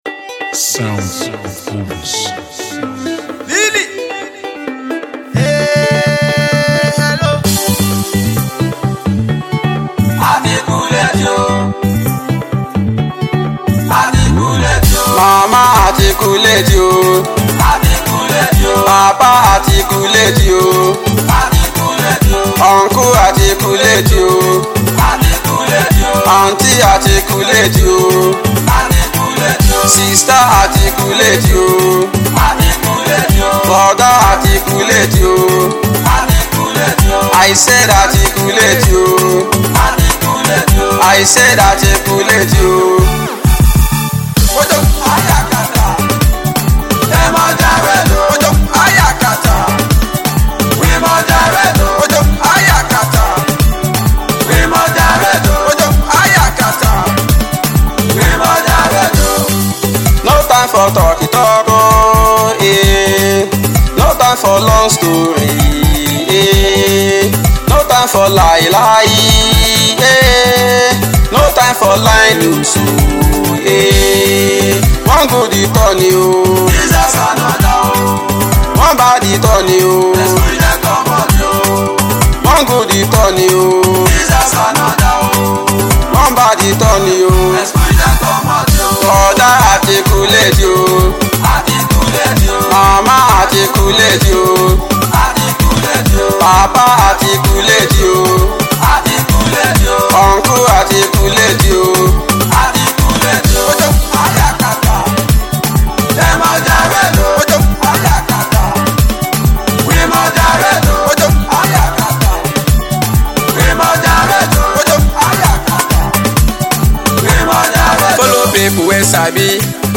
inspiring tune